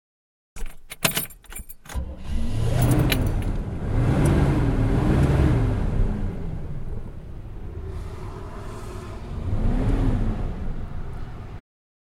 Звук поворота ключа зажигания и запуска двигателя